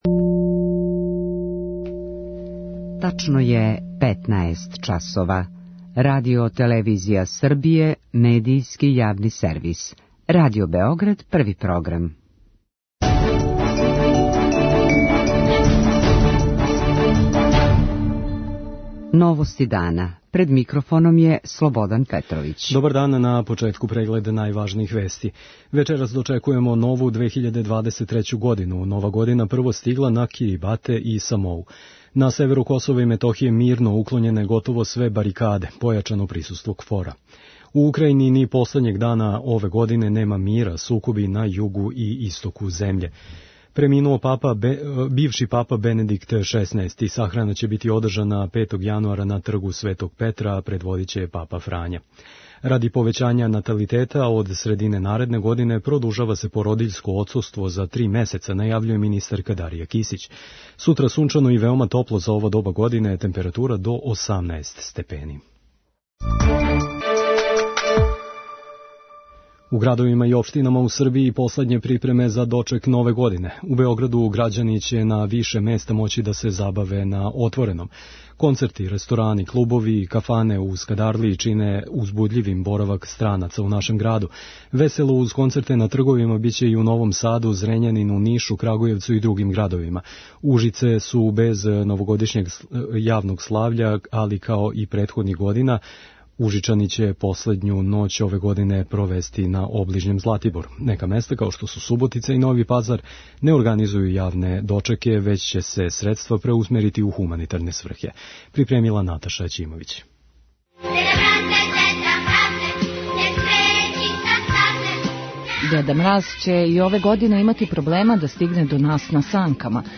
Има и места у којима неће бити организованог славља, већ ће средства бити преусмерена у хуманитарне сврхе. преузми : 6.00 MB Новости дана Autor: Радио Београд 1 “Новости дана”, централна информативна емисија Првог програма Радио Београда емитује се од јесени 1958. године.